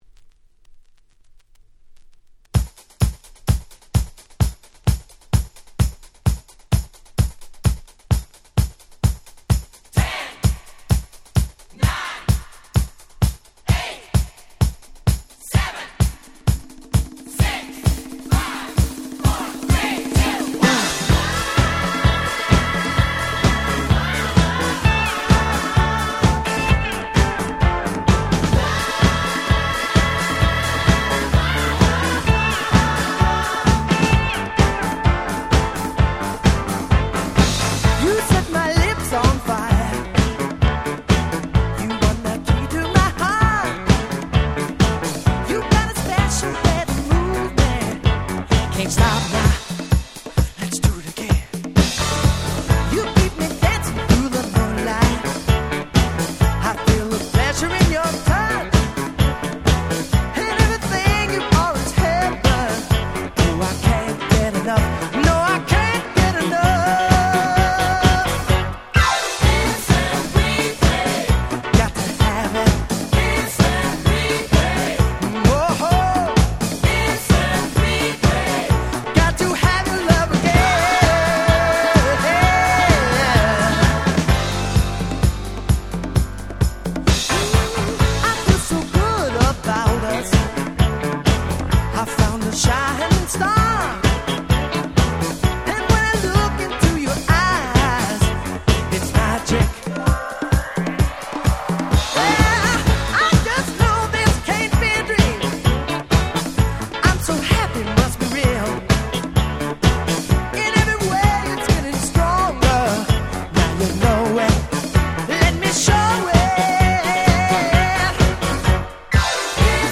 78' Super Hit Disco !!
IntroのCount Downからブチ上がり必須の最強Party Disco !!